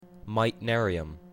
/maɪtˈnɜriəm(米国英語)/